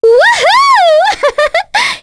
Cleo-Vox-Laugh3.wav